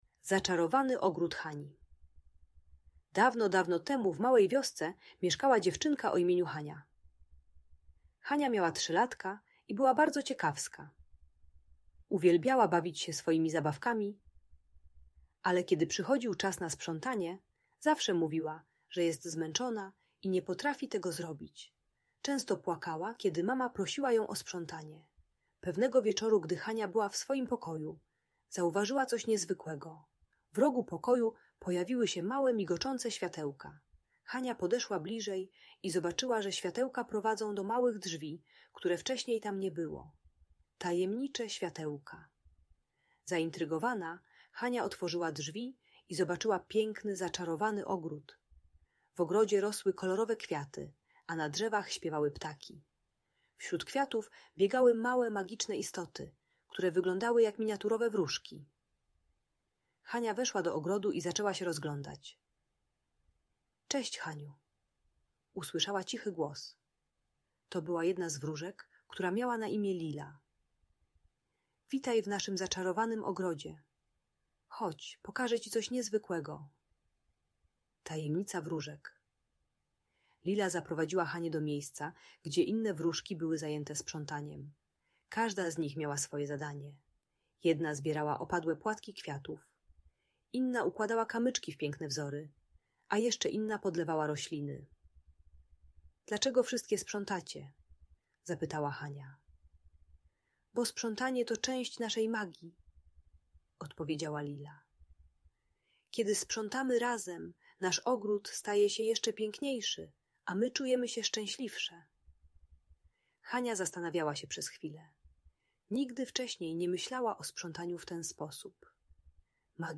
Zaczarowany Ogród Hani - Bajka o Magii i Sprzątaniu - Audiobajka dla dzieci